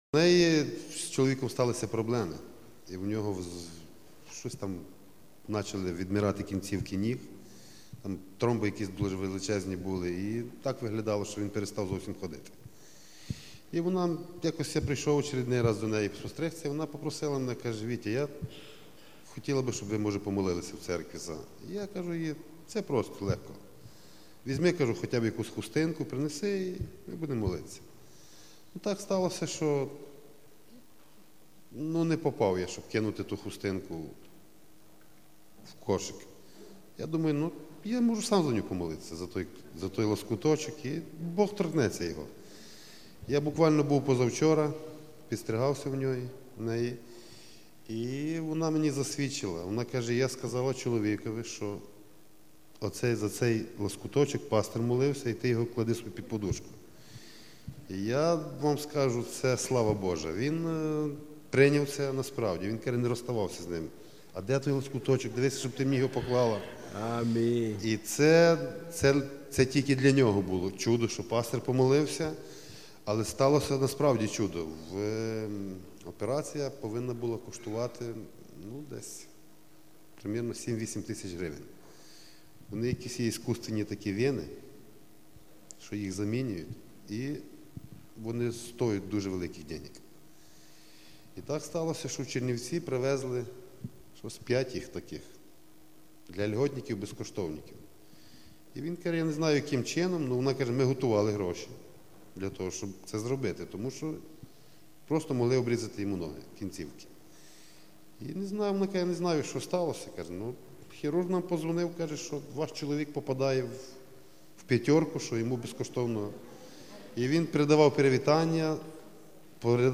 mp3 проповеди на украинском